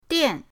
dian4.mp3